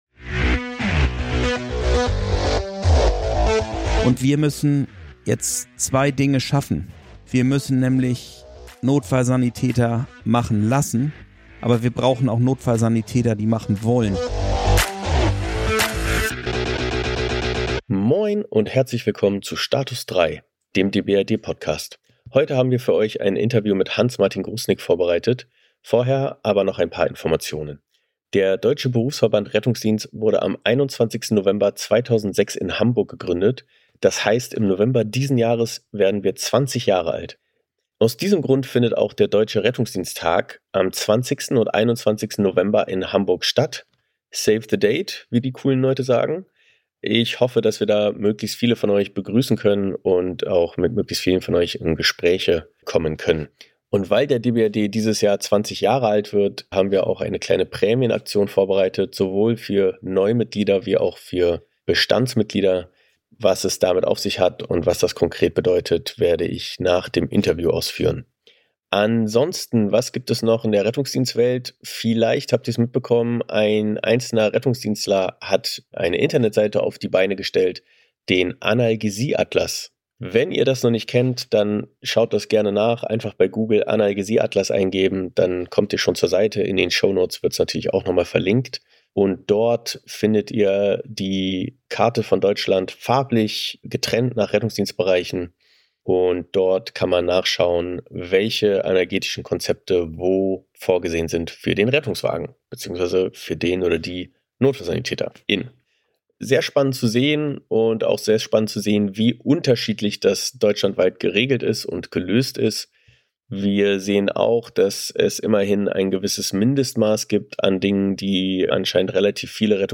Hier schildert ein ÄLRD wie er die Handlungen von NotSan rechtlich einordnet. Warum der § 2a NotSanG so wichtig ist, welche Bedingungen wie erfüllt sein müssen, und was seine Rolle im Rettungsdienst ist.